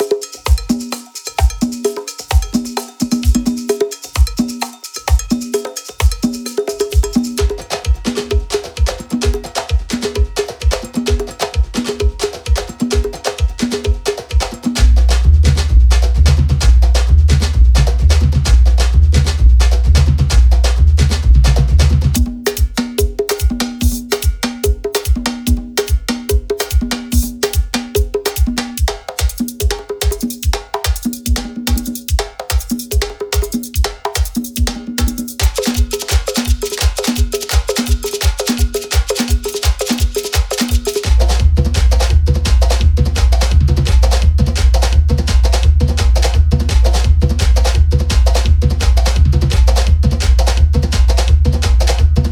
Percussões inspiradas no trio elétrico, com ritmos como Samba-Reggae, Galope e Merengue, ideais para criar hits enérgicos.
Loops e one-shots organizados por instrumentos: Malacaxeta, Surdo, Repique, Timbal e Xequerê.